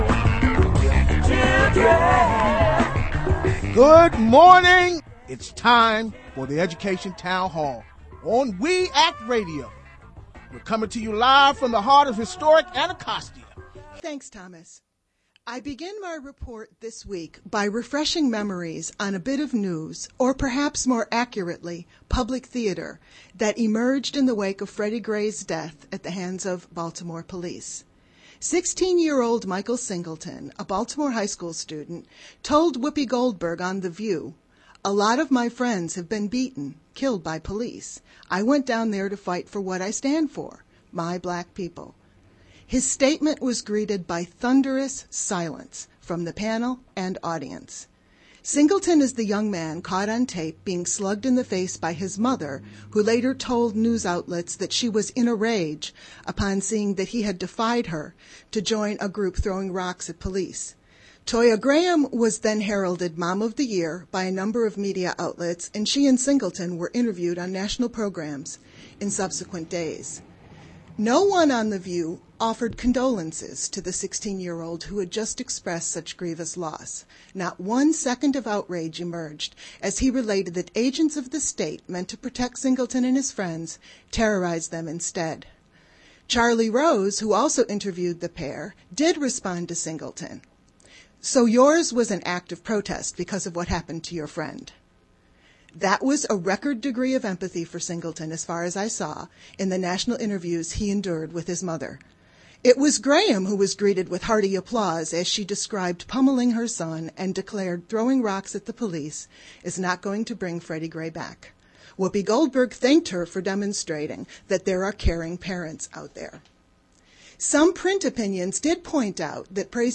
Feature Report
The Education Town Hall broadcasts from Historic Anacostia in Washington, DC, Thursdays